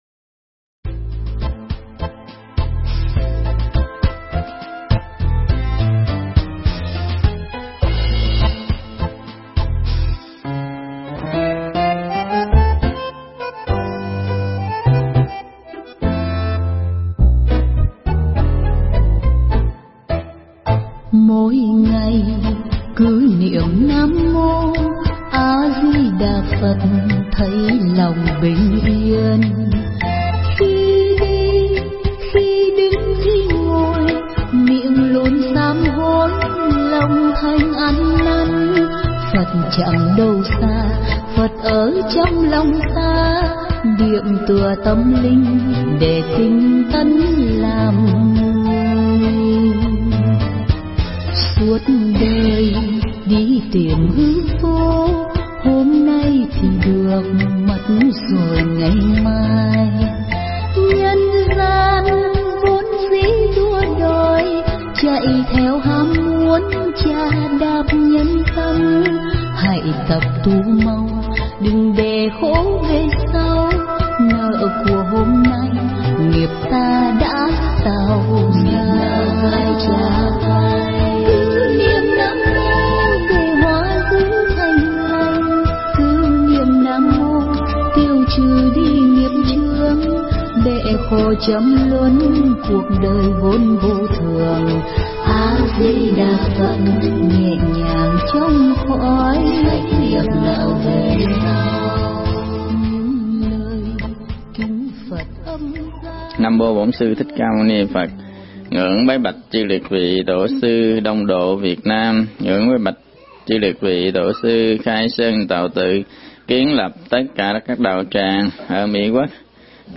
Nghe Mp3 thuyết pháp Người phật tử chân chánh